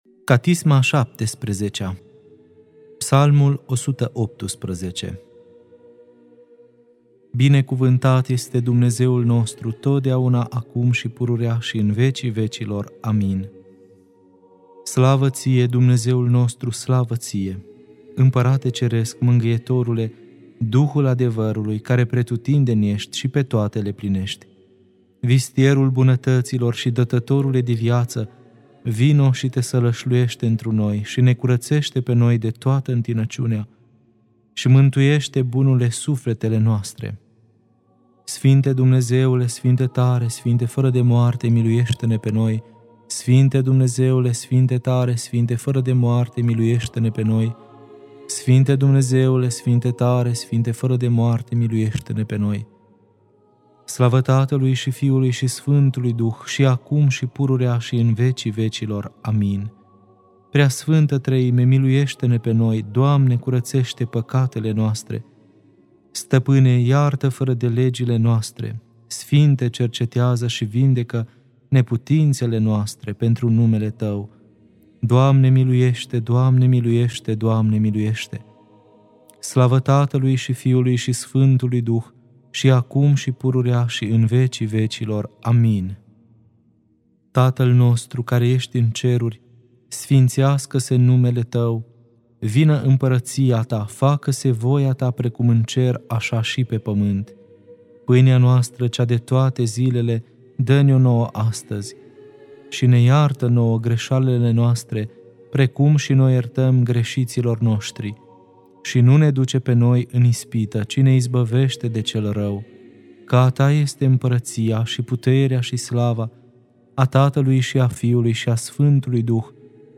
Catisma a XVII-a (Psalmul 118) Lectura